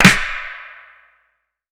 Toxic ClapSnare2.wav